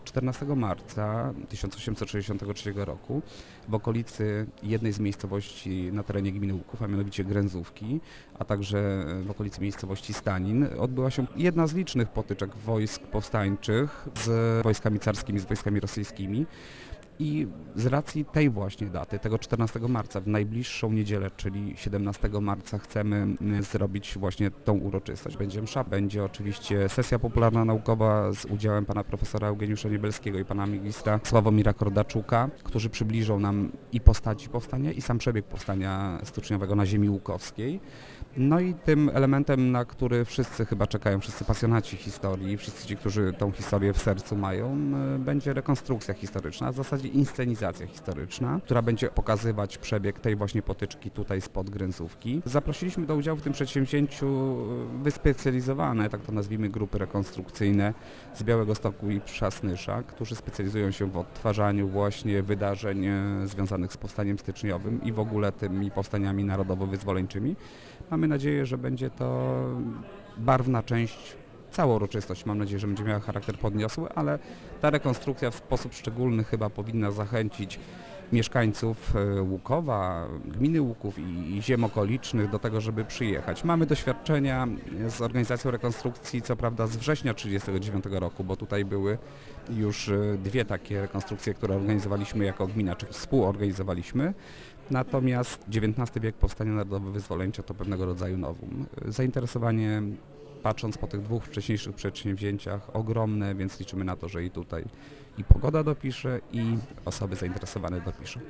Do udziału w uroczystości zaprasza jeden z organizatorów, wójt Mariusz Osiak, który wyjaśnia, dlaczego obchody Powstania Styczniowego są organizowane... w marcu: